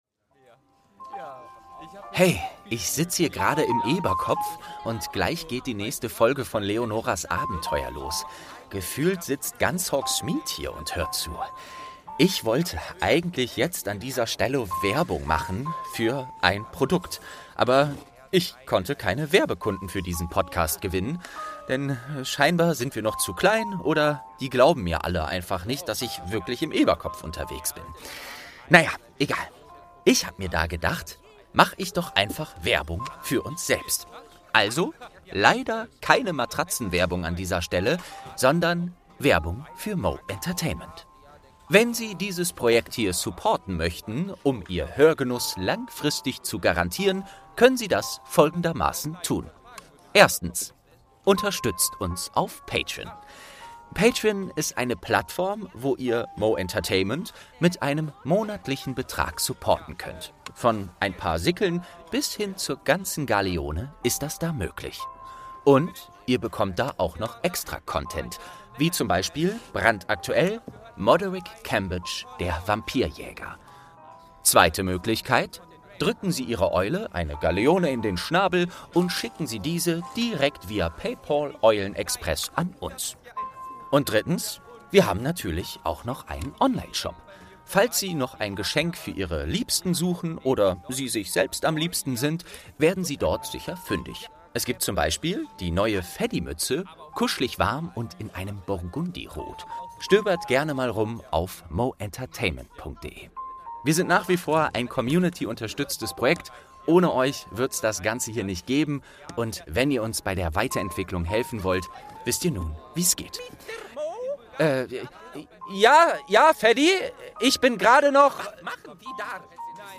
Türchen | Weasleys Muggelfon - Eberkopf Adventskalender ~ Geschichten aus dem Eberkopf - Ein Harry Potter Hörspiel-Podcast Podcast